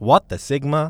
Voice Lines / Dismissive
what the sigma.wav